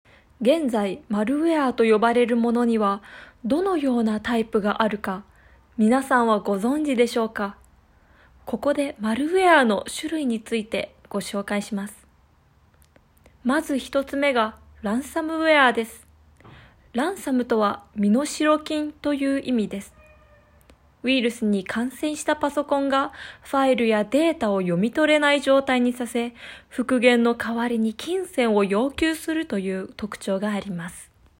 解说旁白